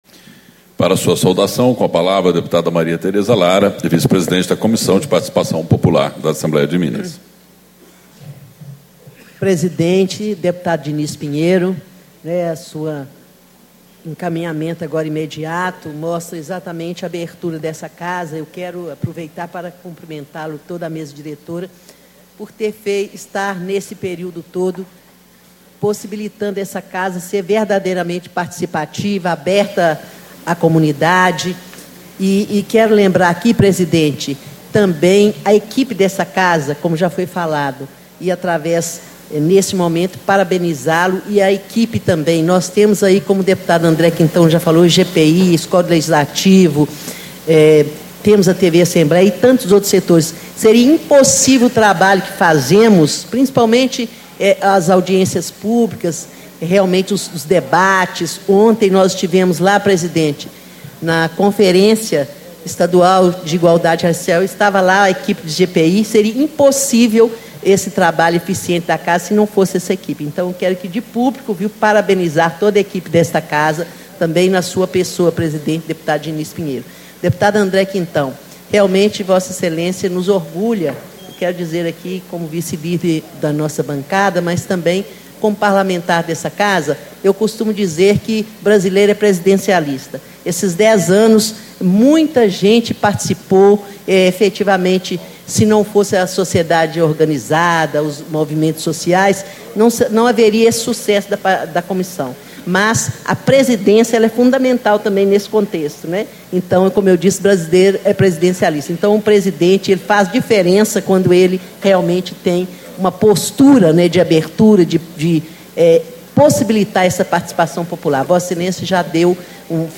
Abertura - Deputada Maria Tereza Lara, PT - Vice-Presidente da Comissão de Participação Popular
Discursos e Palestras